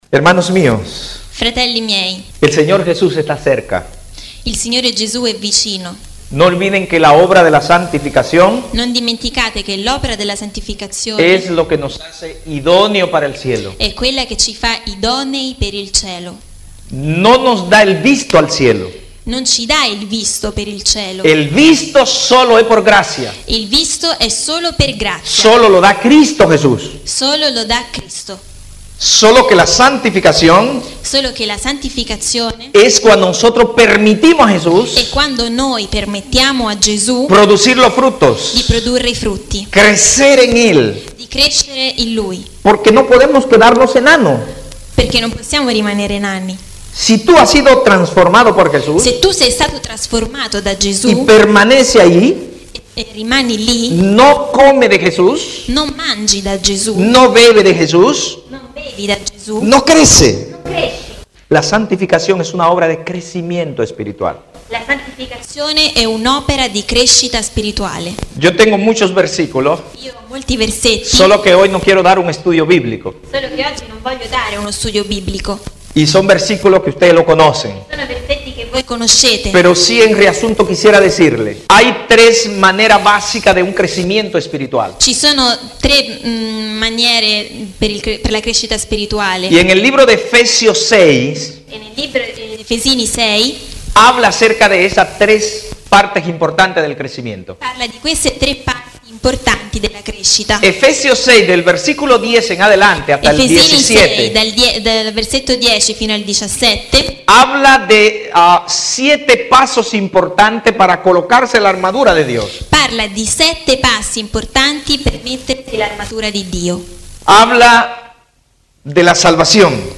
Estratto da una conferenza sul tema della giustificazione per fede